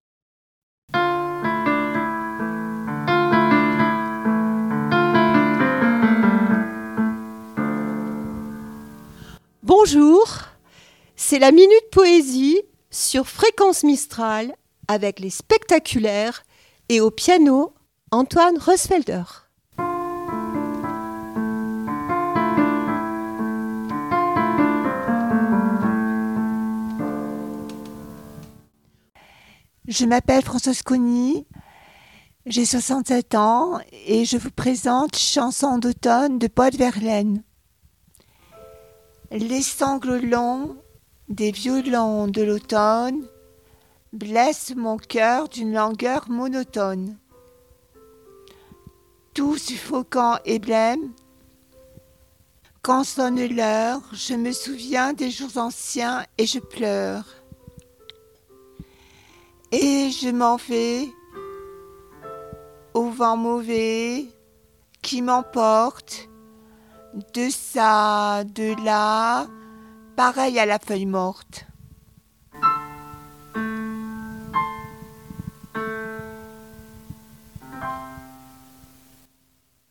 La poésie se murmure au rythme du piano sur Fréquence Mistral
Poesie - Les feuilles mortes.mp3 (913.59 Ko) Chronique poésie sur Fréquence Mistral avec : "les Spectaculaires", une troupe amateur qui fait s’envoler les mots au son du piano, une parenthèse sonore où les mots dansent , entre poésie et émotion s.